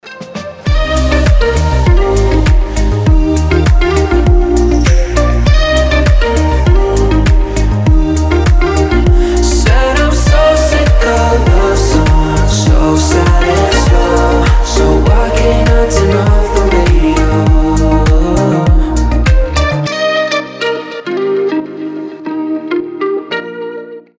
• Качество: 320, Stereo
красивый мужской голос
мелодичные
приятные
теплые